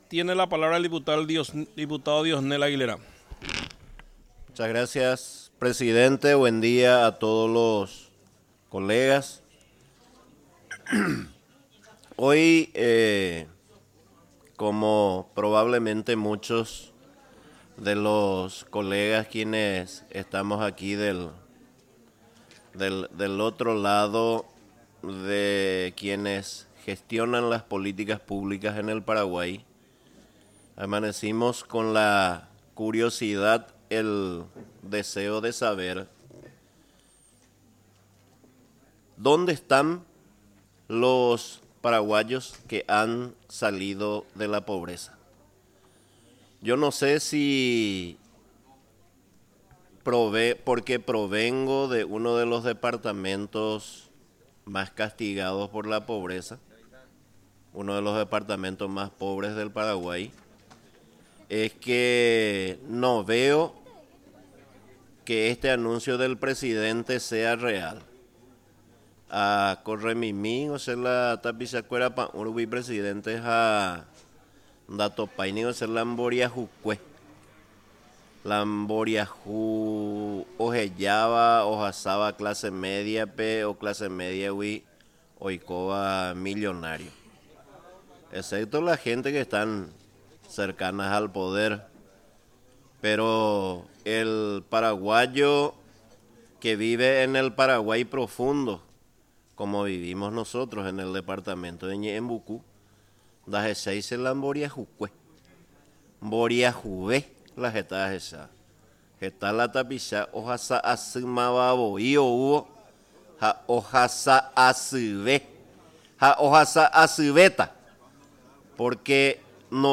Sesión Extraordinaria, 26 de agosto de 2025
Exposiciones verbales y escritas
08 – Dip Rocío Vallejo